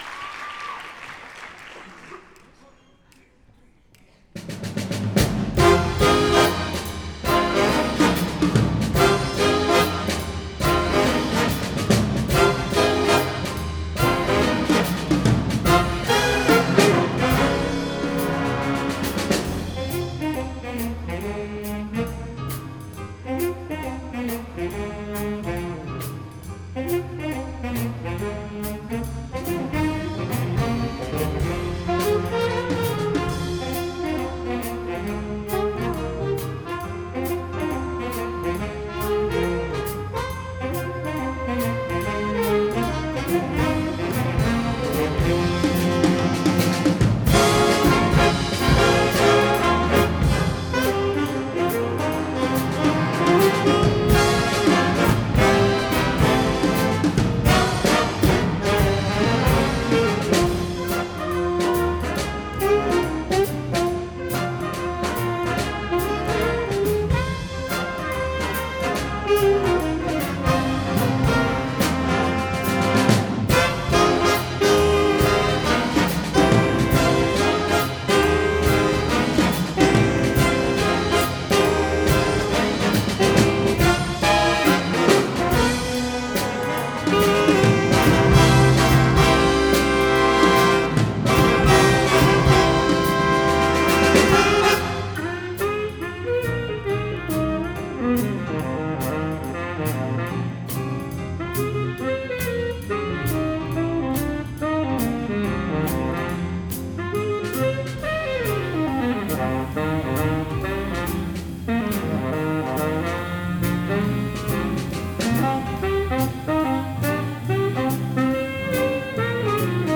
01 Groovin Hard Live M 4 A